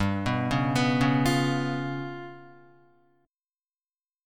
G7b5 chord